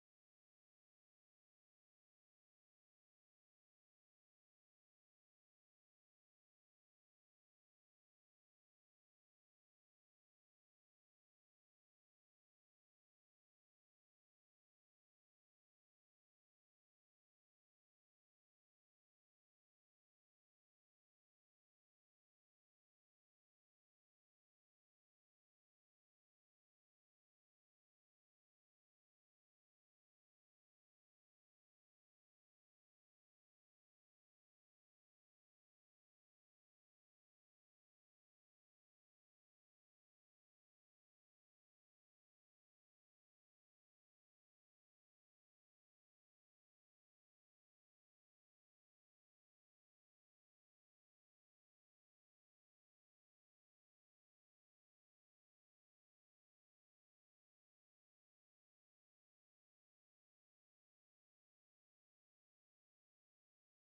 Josh Inglis Perth Scorchers speaks to the media ahead of their match against the Sydney Sixers